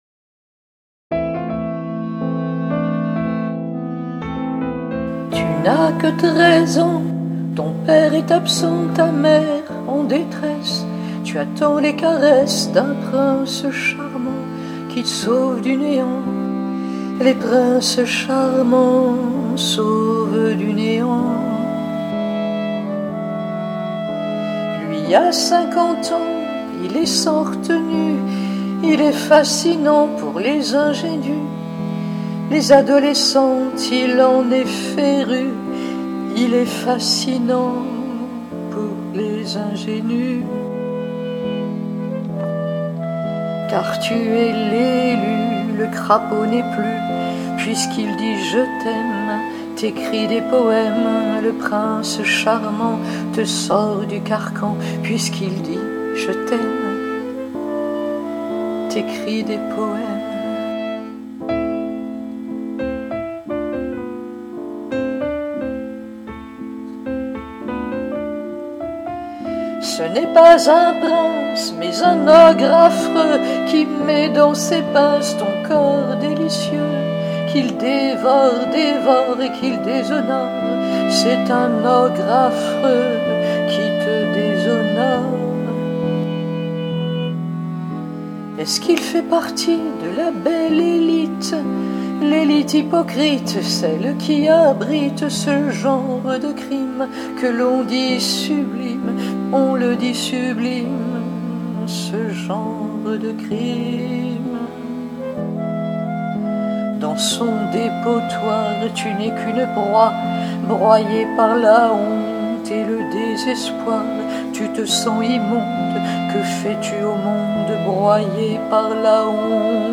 Accompagnement et Arrangement studio